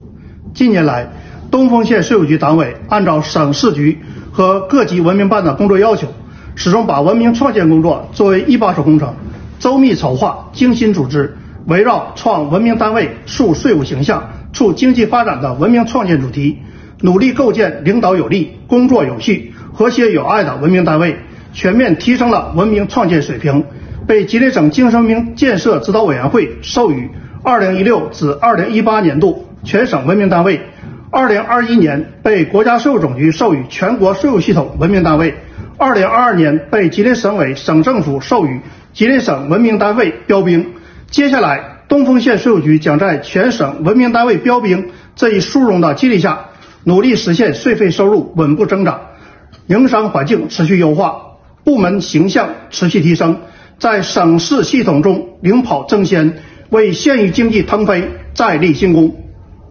国家税务总局东丰县税务局党委书记、局长段争新说，